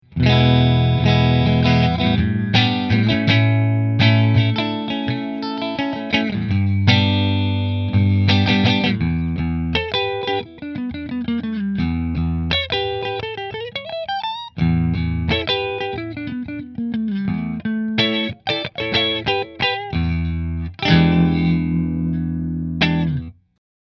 It has the snappiness from the Les Paul Special with it’s P90s, but also has the rounded tone from the semi-hollow design of the Telecaster Deluxe.
• Three Custom Wound P90s
New Orleans Guitars Model-8 Sunburst Middle Through Fender